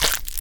Minecraft Version Minecraft Version latest Latest Release | Latest Snapshot latest / assets / minecraft / sounds / block / frogspawn / hatch2.ogg Compare With Compare With Latest Release | Latest Snapshot